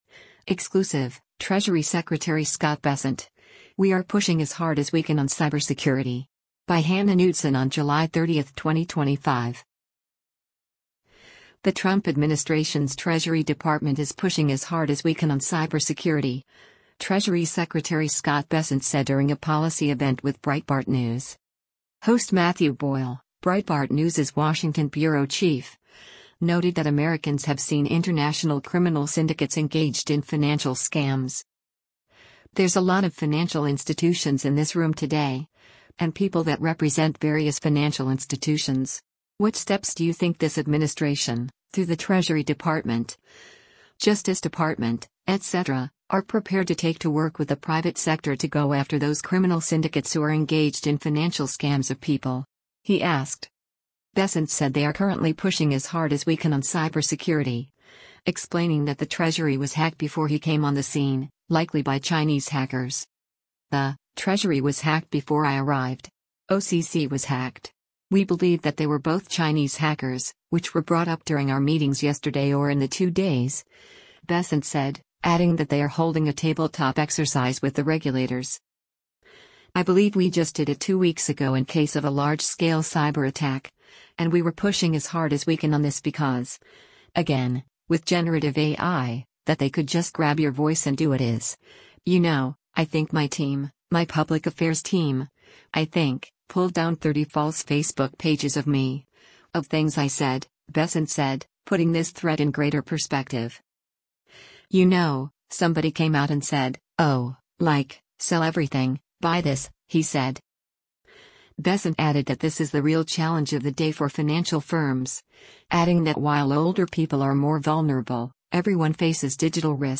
The Trump administration’s Treasury Department is “pushing as hard as we can” on cybersecurity, Treasury Secretary Scott Bessent said during a policy event with Breitbart News.